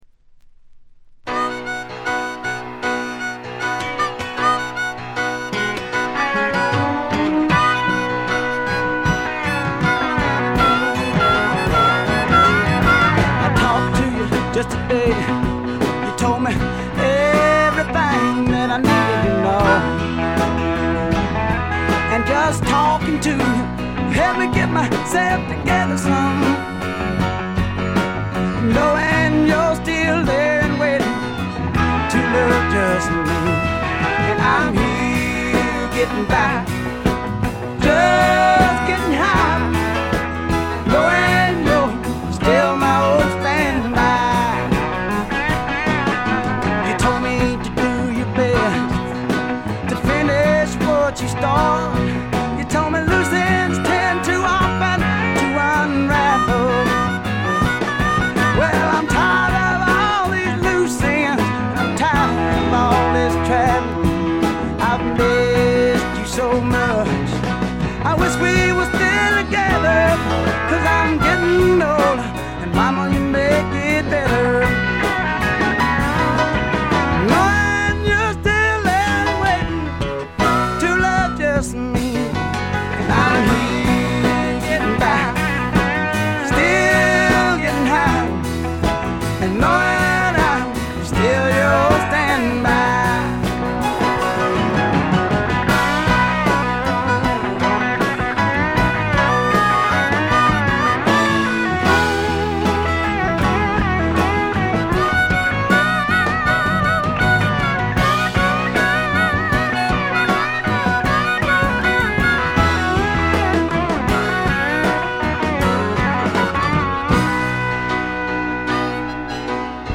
部分試聴ですがほとんどノイズ感無し。
びしっと決まった硬派なスワンプ・ロックを聴かせます。
試聴曲は現品からの取り込み音源です。
Recorded at Paramount Recording Studio.